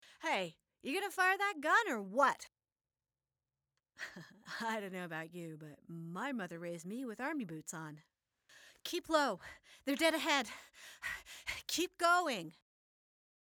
Female
A voice that's warm, youthful, articulate, sweet, friendly, and also sometimes sassy, or even mischievous. Very clear enunciation.
Video Games
Npc Army Commander